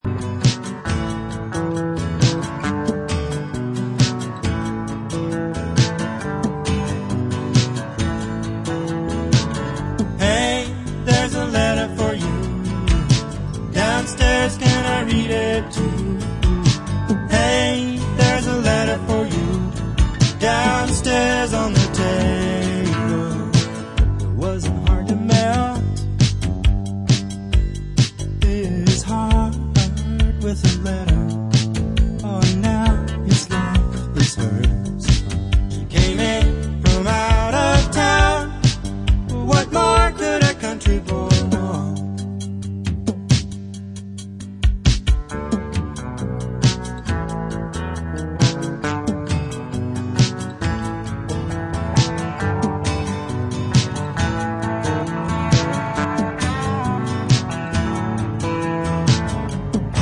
Acoustic
Country, Folk, Pop rock